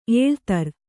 ♪ ēḷtar